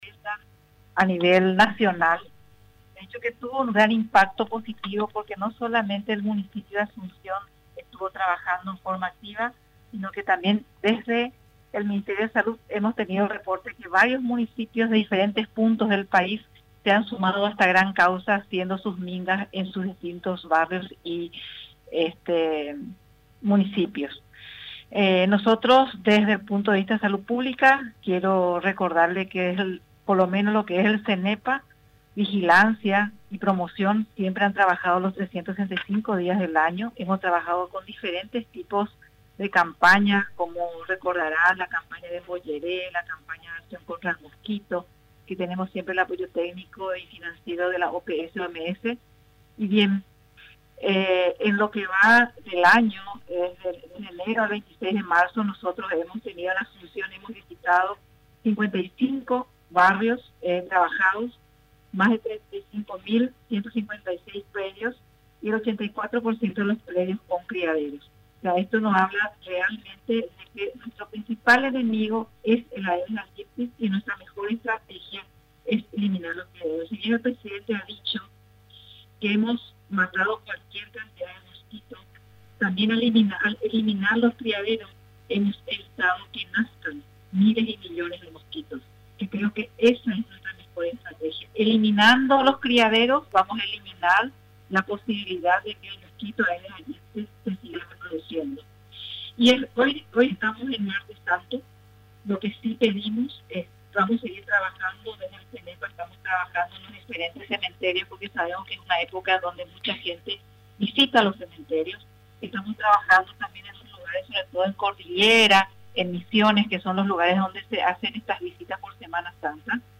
La Viceministra de Salud, doctora María Teresa Barán explicó que la educación de los niños es fundamental para instalar ese cambio de actitud en nuestro país.